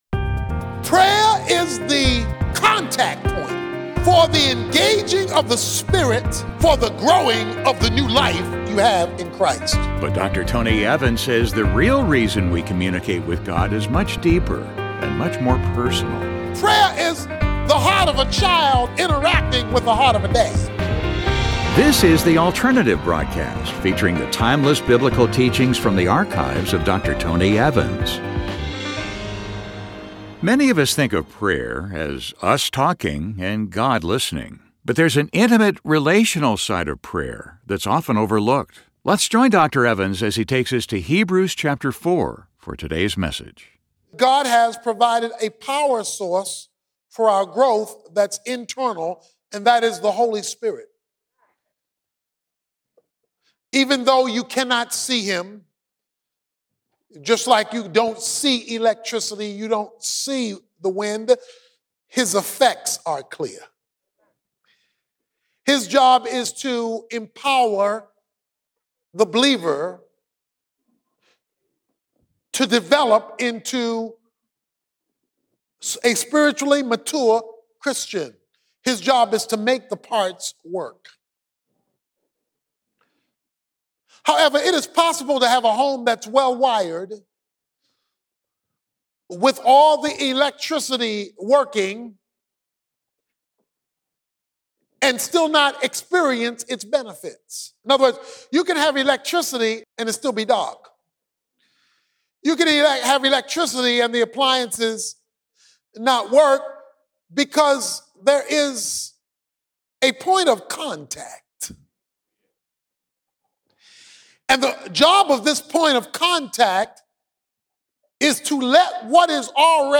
In this message, Dr. Tony Evans explores the intimate, relational side of prayerÂ that many believers overlook.